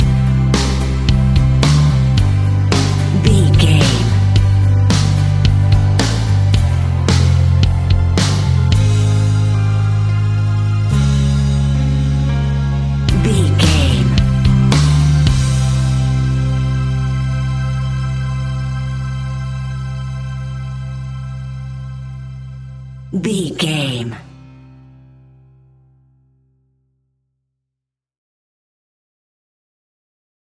Soft Rock Piano Pop Ballad 15 Sec.
Ionian/Major
calm
melancholic
smooth
uplifting
electric guitar
bass guitar
drums
pop rock
indie pop
instrumentals
organ